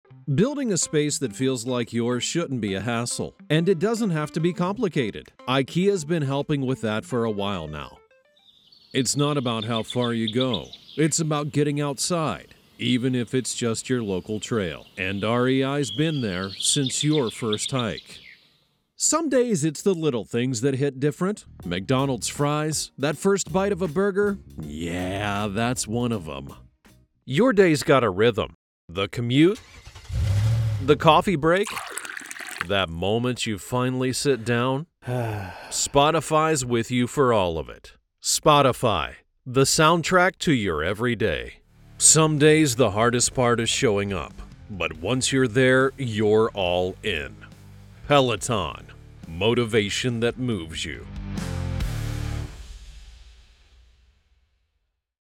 Anglais (Américain)
Profonde, Naturelle, Amicale, Chaude, Corporative
Corporate